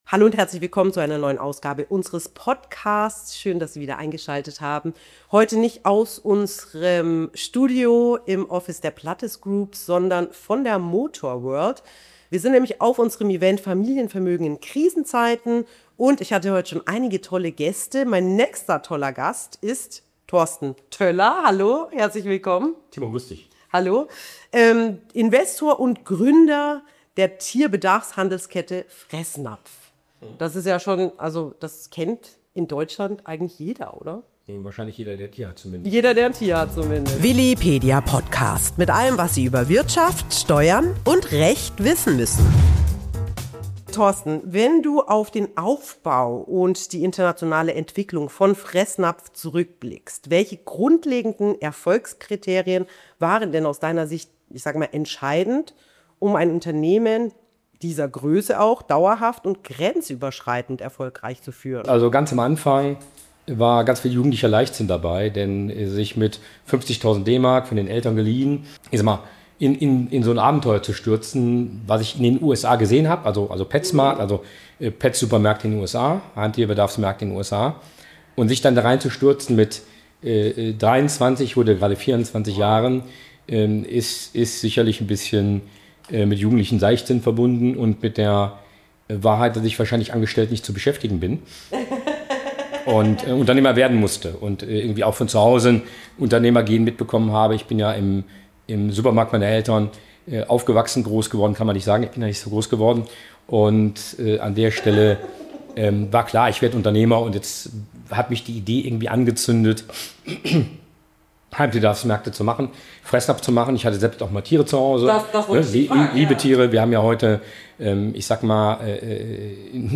Beschreibung vor 6 Tagen Wie baut man mit 23 Jahren und 50.000 DM Startkapital ein Unternehmen auf, das heute über 2.800 Standorte in 15 Ländern umfasst? In dieser Podcastfolge spricht Torsten Toeller offen über seinen Weg vom jungen Gründer zum internationalen Unternehmer.